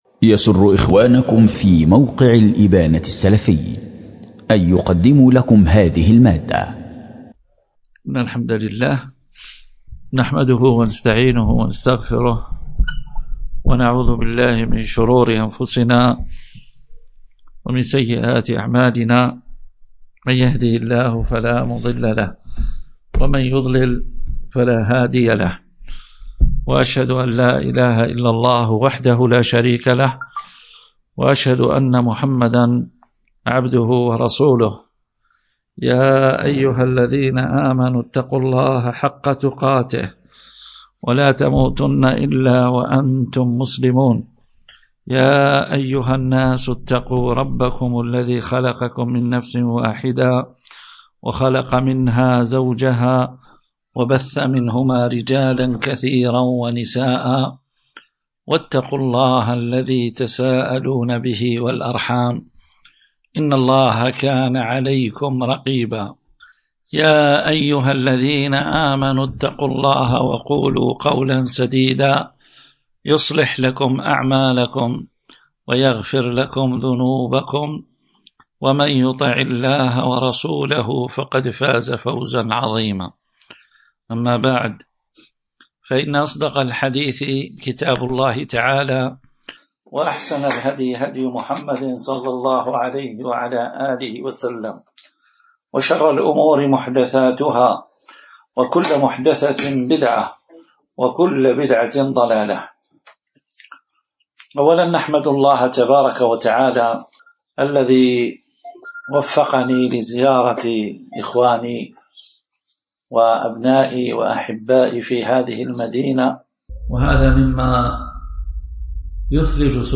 تأريخ: 05 ربيع الأول 1440 ، الموافق 13 نوفمبر 2018مـ 🔹 مدينة سيدي عيسى ولاية مسيلة .
الوسوم: دروس ومحاضرات